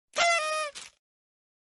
confetti.mp3